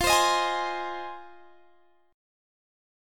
Fm7 Chord
Listen to Fm7 strummed